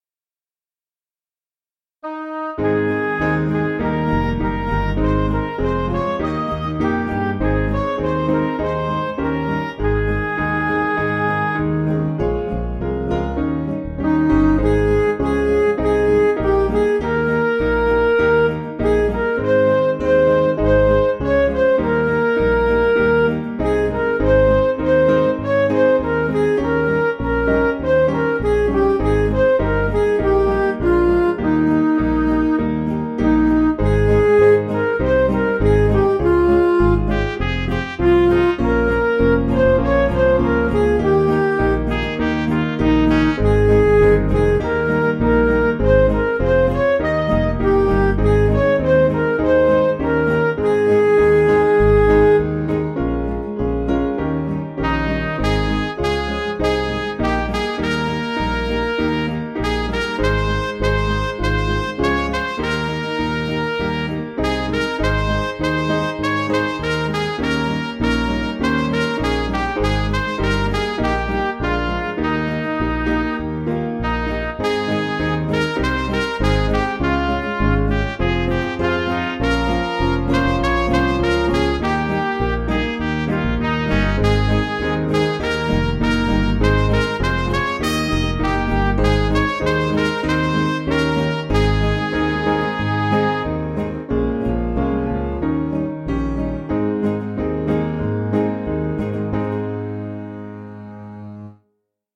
Piano & Instrumental
(CM)   2/Ab